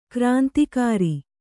♪ krāntikāri